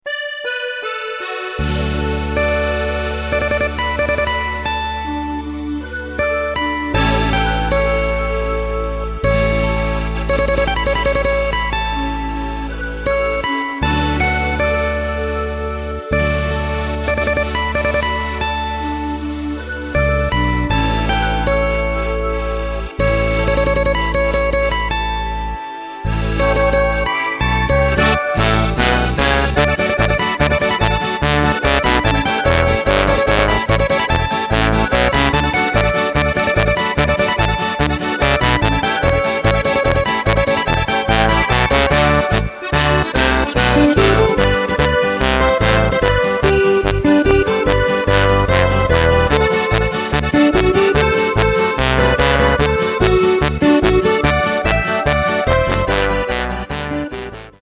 Takt:          2/4
Tempo:         78.50
Tonart:            D
Polka aus dem Jahr 2012!